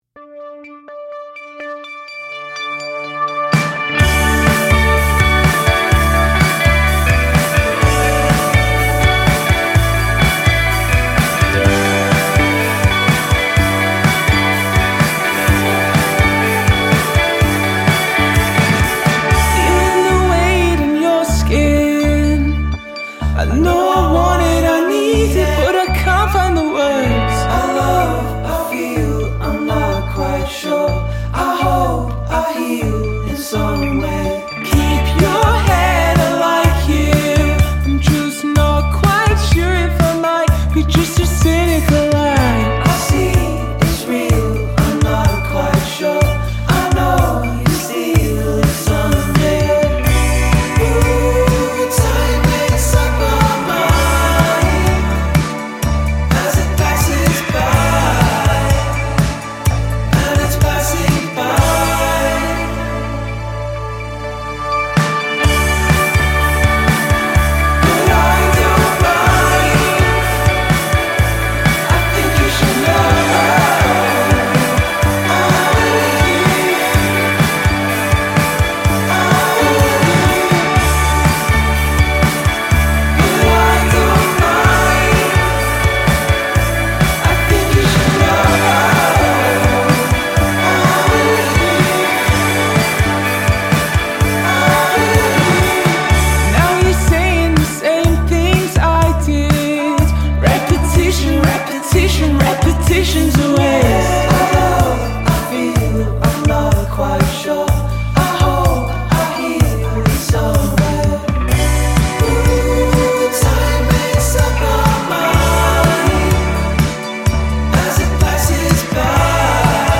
The indie four-piece
vocals/guitar
bass/backing vocals
drums